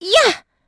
Estelle-Vox_Attack6.wav